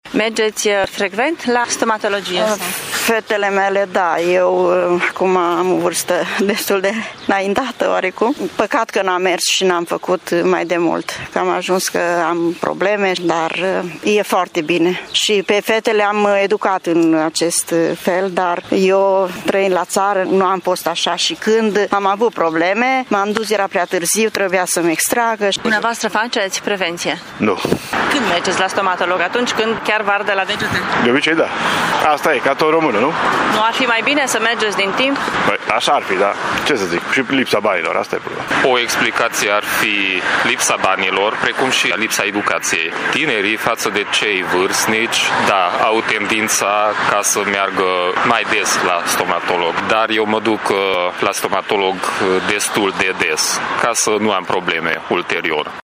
Târgumureșenii recunosc că au o problemă atunci când vine vorba de prevenția îmbolnăvirilor, însă problema este de natură financiară: